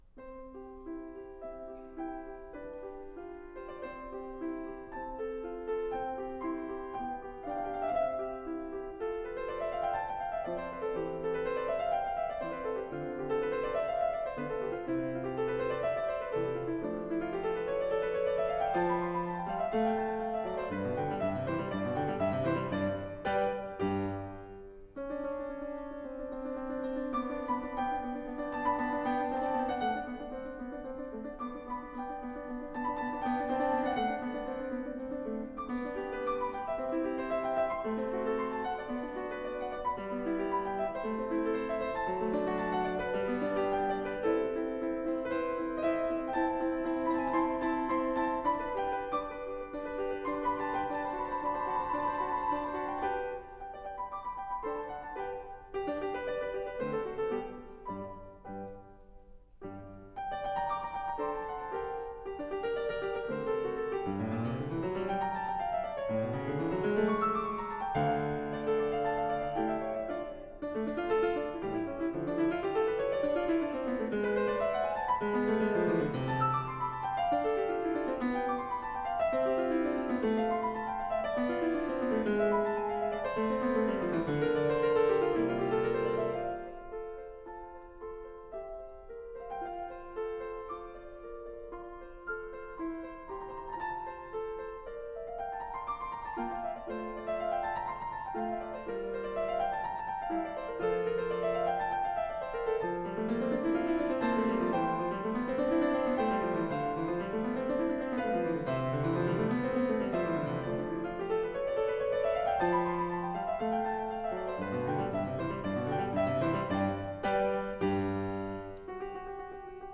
The selections below are from a concert I played called "The Essential Pianist".